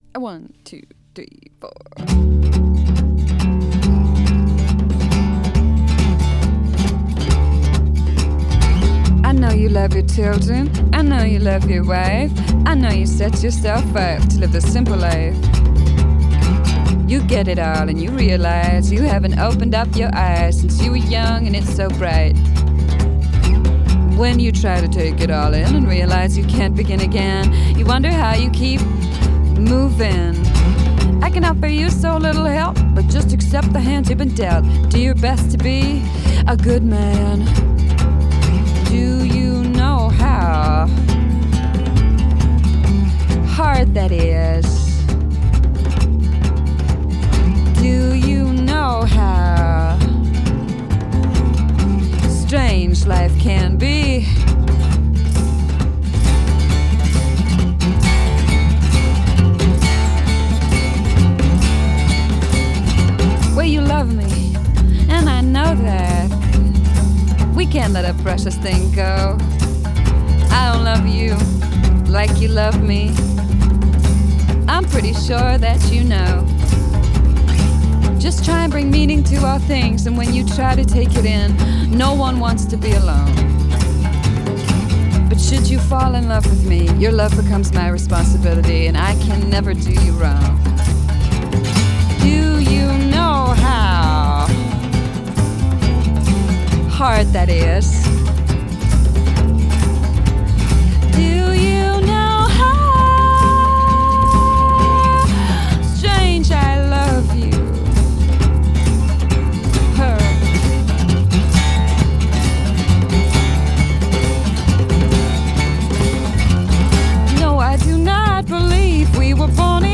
Genre: Indie Folk, Alternative